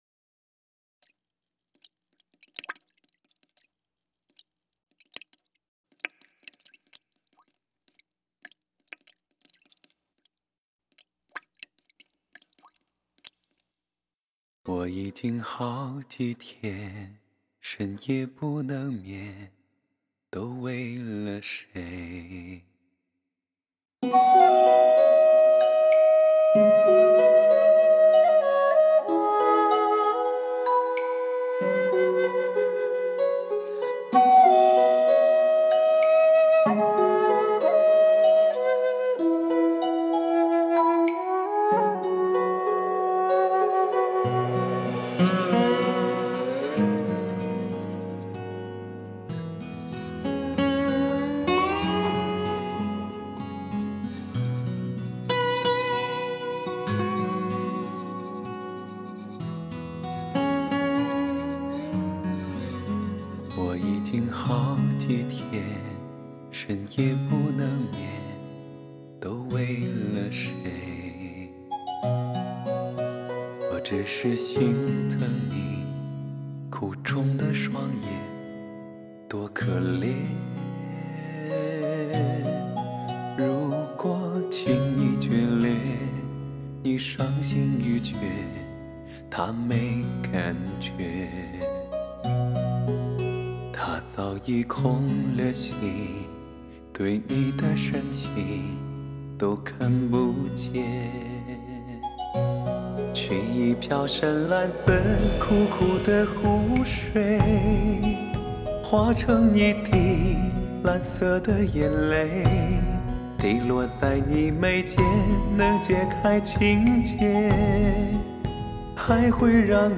特级电影声效6.1DTS示范音乐。
至真至靓至美人声顶级发烧录音制作，神气、逼真的环绕声音乐。